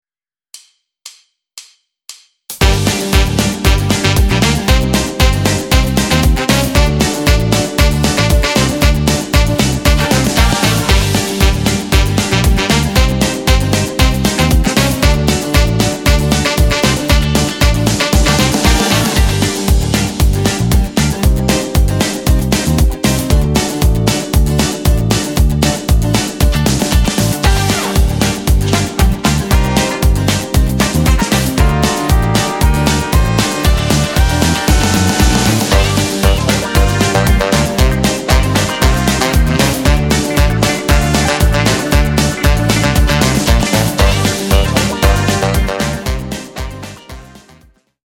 podkład dla wokalistów
MP3 BEZ DĘCIAKÓW